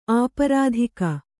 ♪ āparādhika